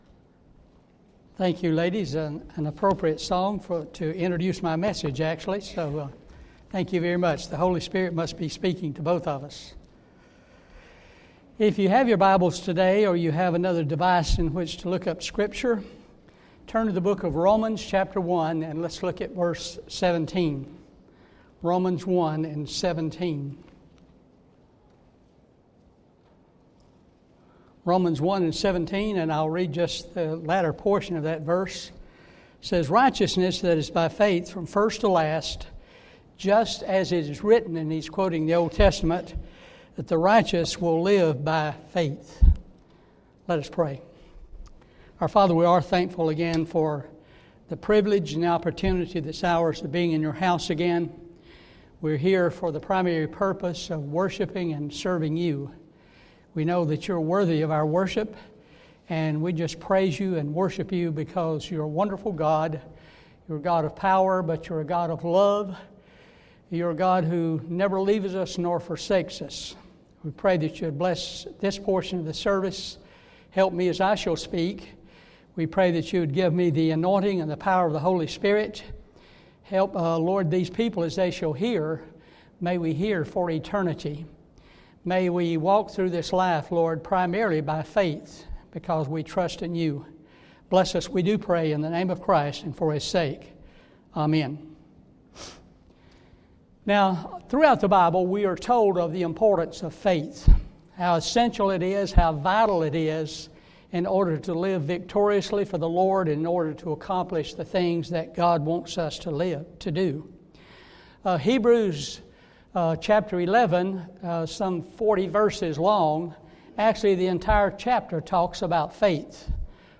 Sermons Archive - Page 5 of 35 -